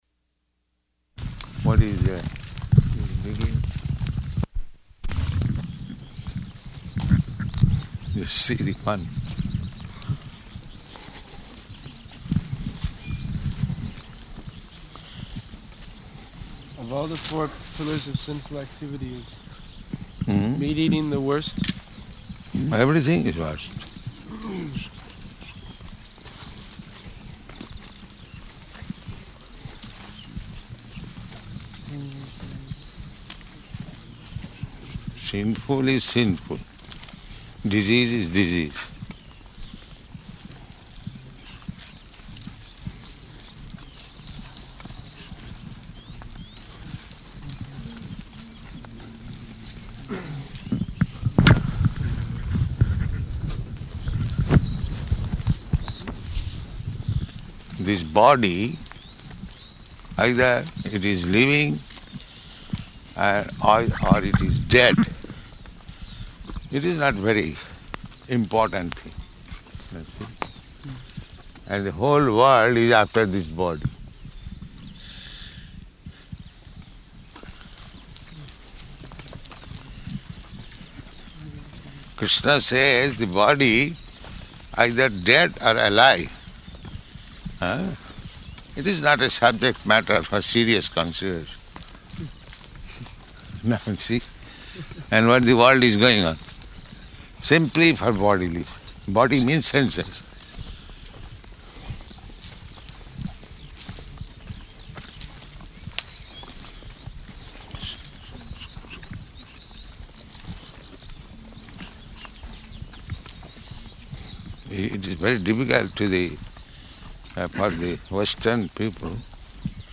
Morning Walk --:-- --:-- Type: Walk Dated: June 17th 1974 Location: Germany Audio file: 740617MW.GER.mp3 Prabhupāda: What is there?